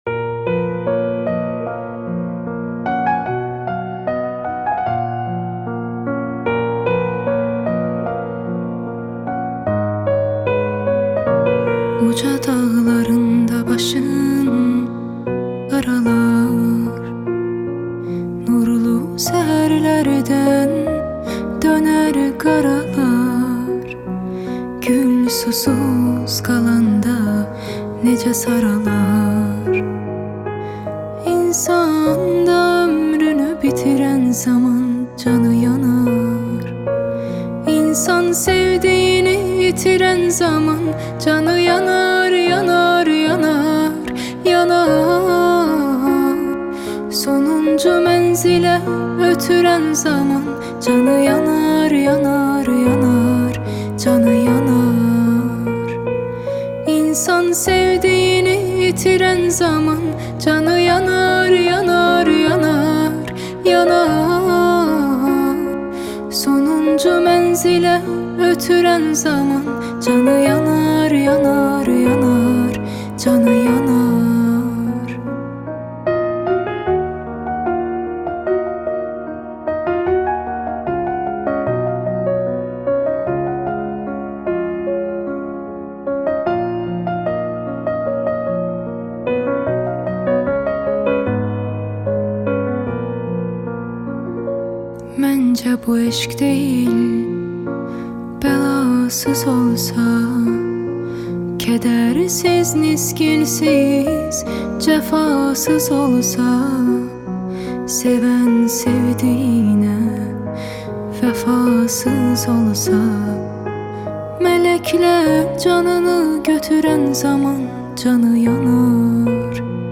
با صدای دختر